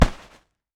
Gloves Hit Distant.wav